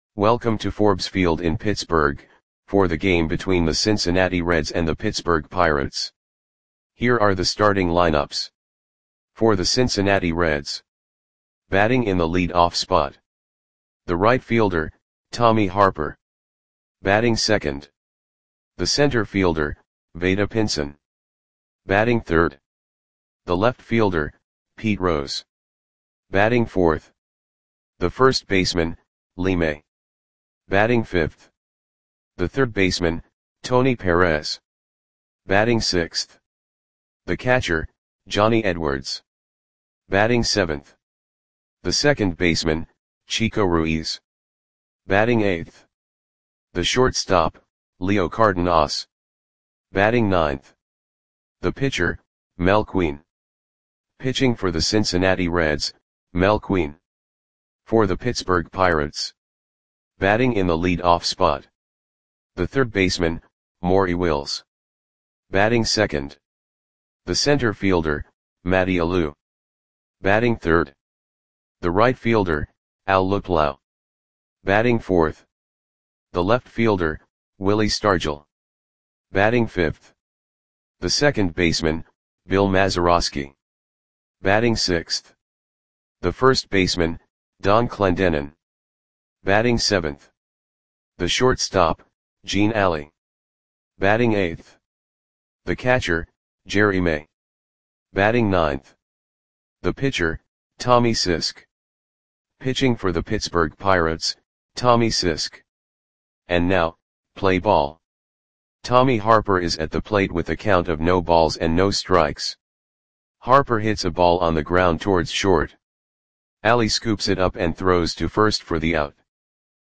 Audio Play-by-Play for Pittsburgh Pirates on August 15, 1967
Click the button below to listen to the audio play-by-play.